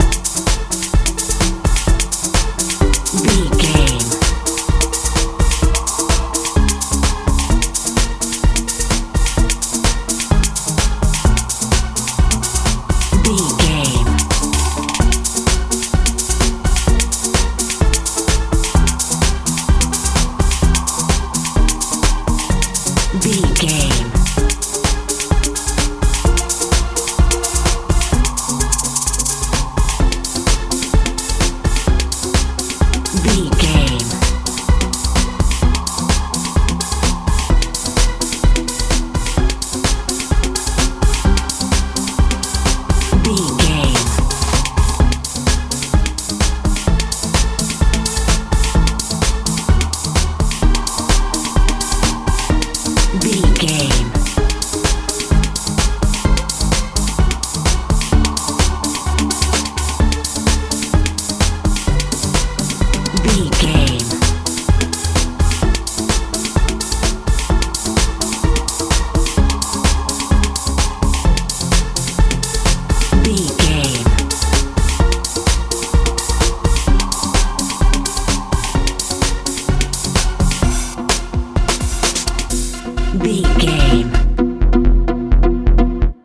euro dance
Ionian/Major
dreamy
futuristic
bass guitar
synthesiser
drums
80s
90s